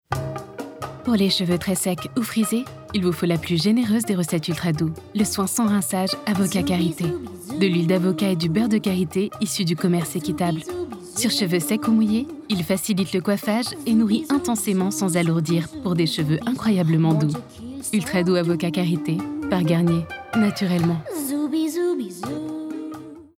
PUB DECATHLON
Une voix qui ne triche pas, et une présence simple, chaleureuse et pleine de vie.
13 - 45 ans - Mezzo-soprano